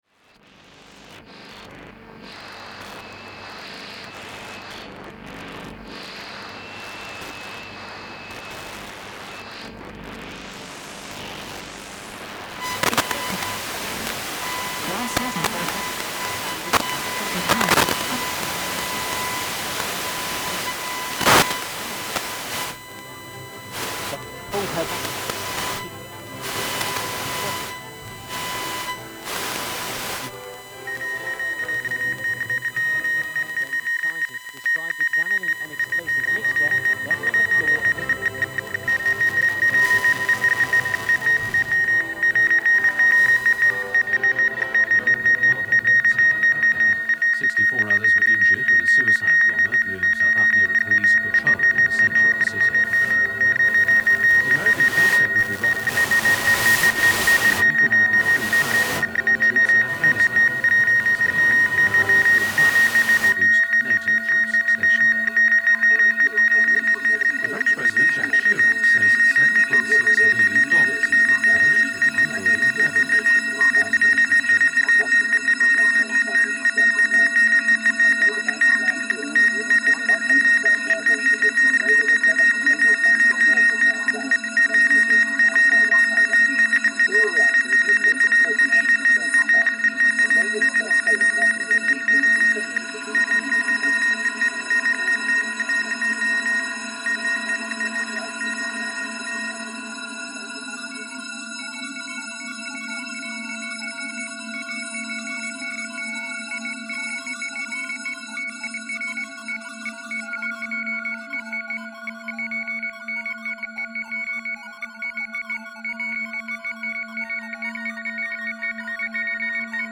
I have made a piece that can be seen as three units that fit together under the themes of communication, drifting and the void. The units are divided by the sound of the turning of a dial on a radio searching for transmissions. The piece develops with willfully retro communication sounds referring back to the possibilities and imaginary worlds that radio provided in its infancy.